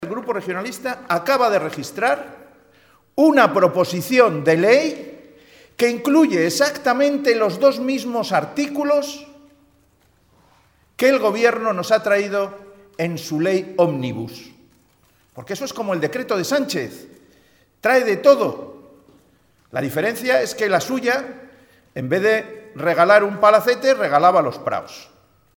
Ver declaraciones de Pedro Hernando, portavoz parlamentario del Partido Regionalista de Cantabria.
Pedro Hernando en un momento de la rueda de prensa que ha ofrecido hoy